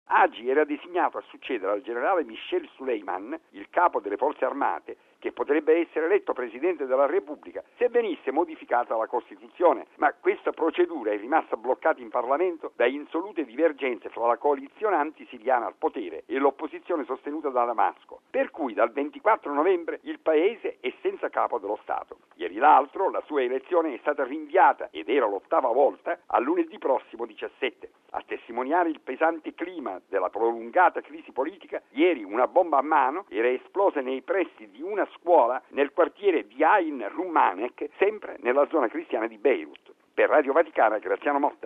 Questa mattina una potente autobomba è esplosa a Baabda, un sobborgo cristiano ad est della capitale, dove si trova anche il palazzo presidenziale. Tra le vittime anche il generale Francois al-Hajj, comandante operativo dell'Esercito libanese. Il servizio